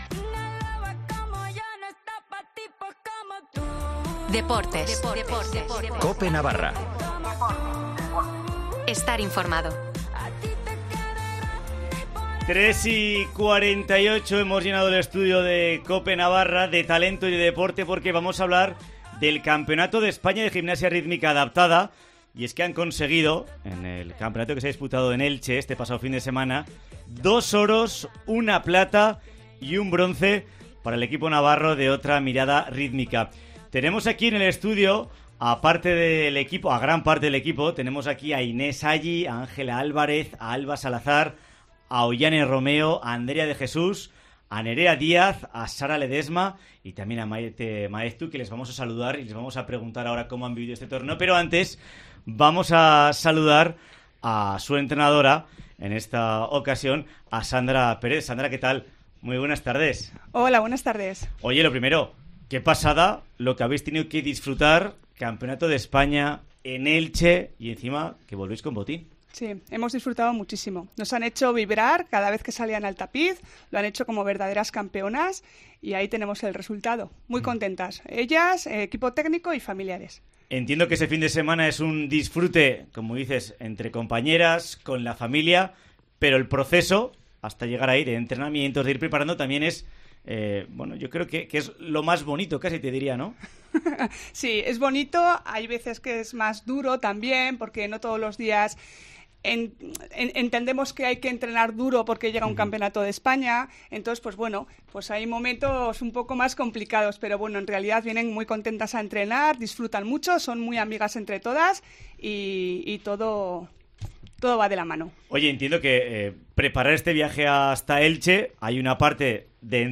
Las gimnastas Otra Mirada Rítmica han estado en el estudio de COPE Navarra